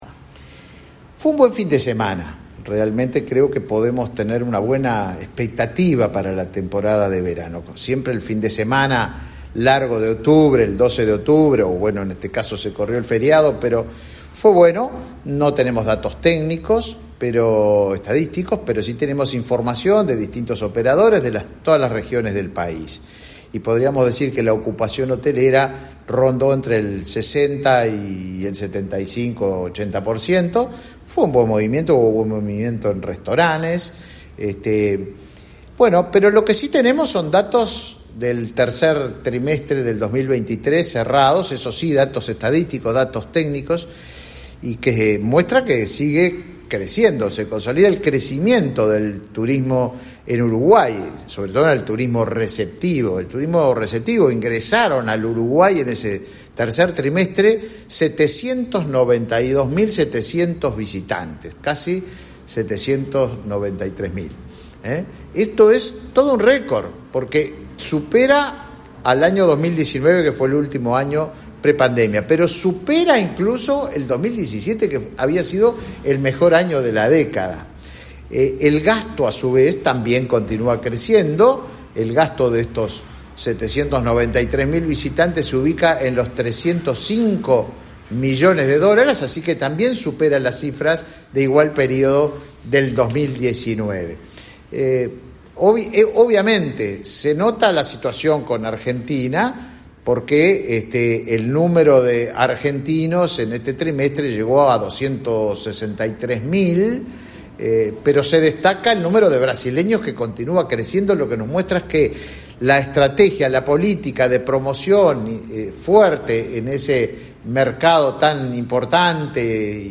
Palabras del ministro de Turismo, Tabaré Viera